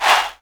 LL_chant.wav